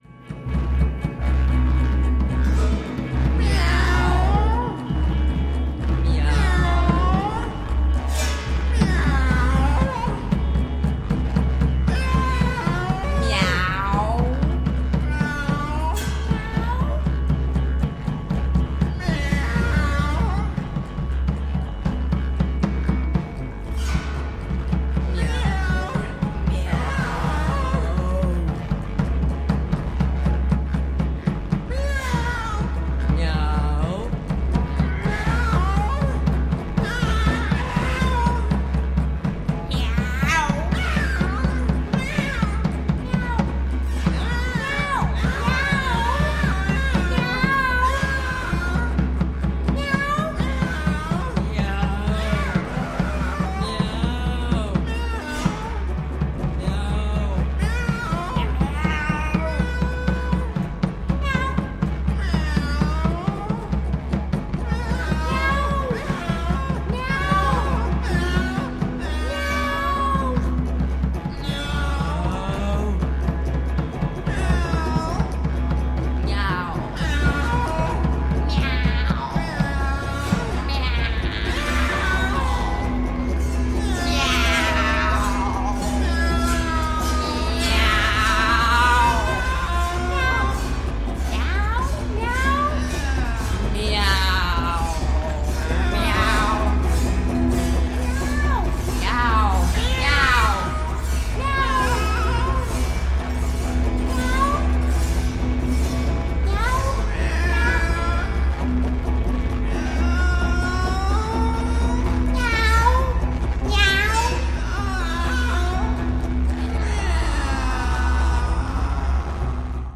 Again, without rehearsals or repeats.
All three are non-educated and non-professional musicians.